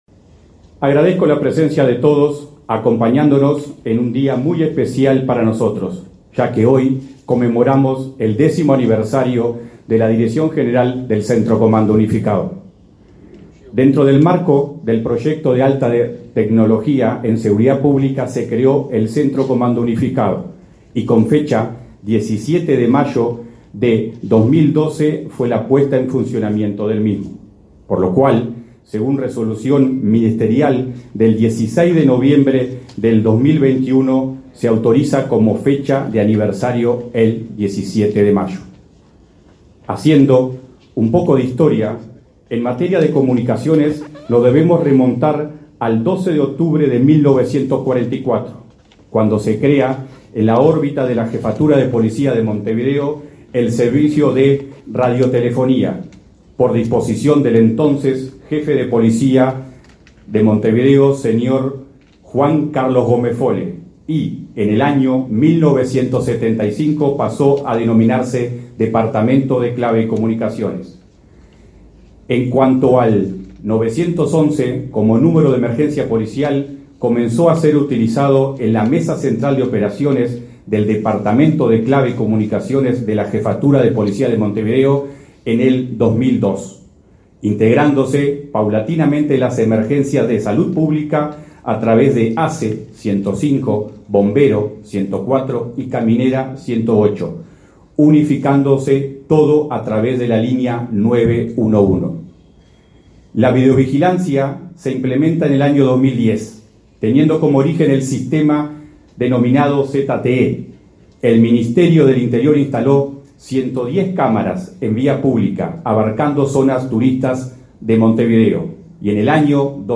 Aniversario de la Dirección General del Centro de Comando Unificado 17/05/2022 Compartir Facebook X Copiar enlace WhatsApp LinkedIn El director del Centro de Comando Unificado del Ministerio del Interior, Alejandro Sánchez, y el titular de esa cartera, Luis Alberto Heber, destacaron la gestión de esa dependencia, a diez años de su creación.